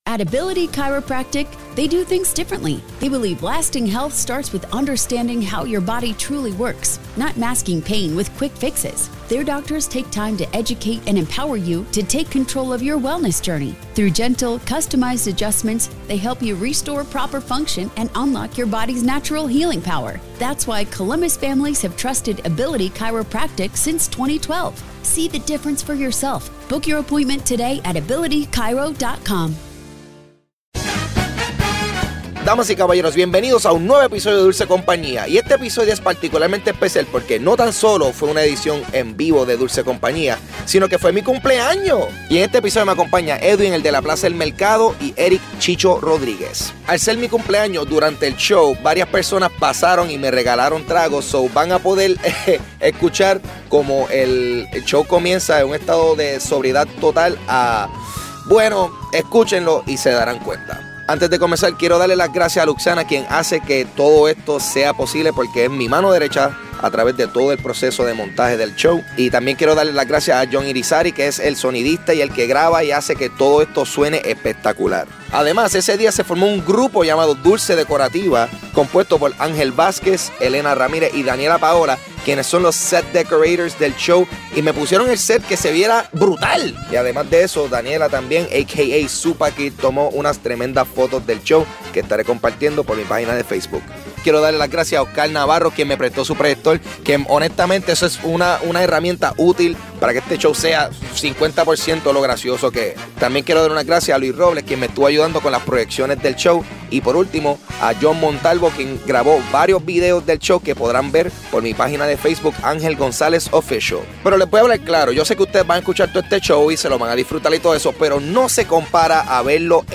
Live en Celebrate
Grabado el 1 de noviembre de 2015 en Celebrate.